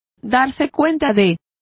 Complete with pronunciation of the translation of discover.